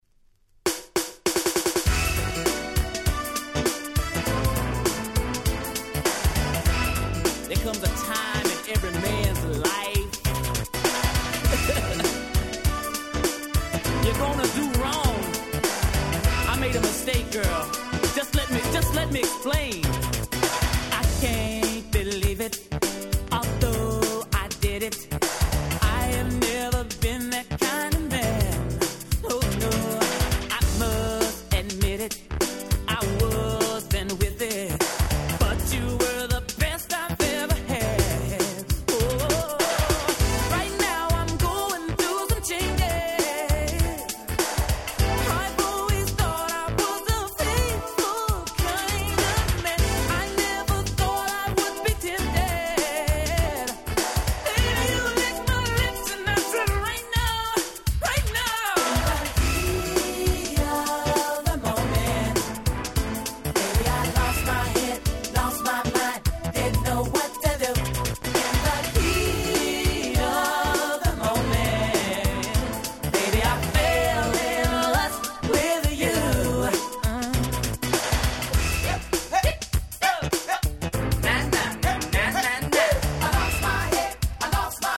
89' Big Hit R&B LP !!